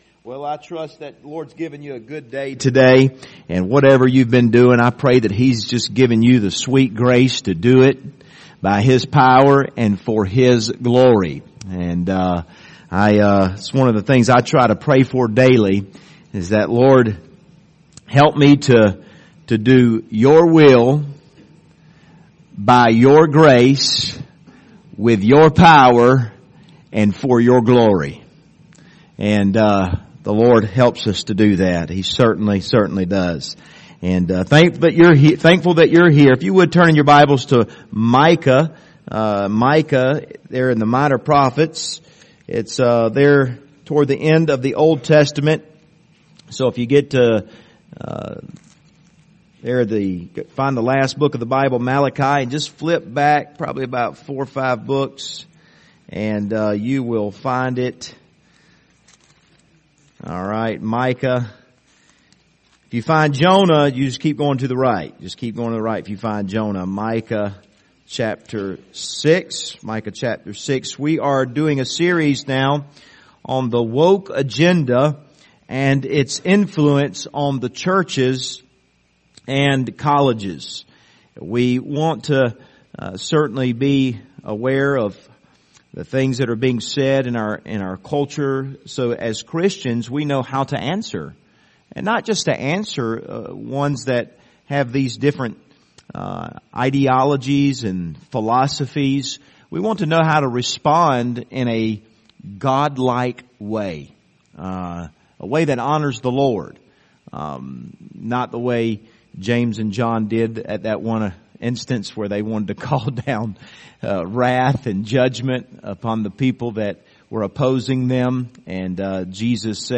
Passage: Micah 6:8 Service Type: Wednesday Evening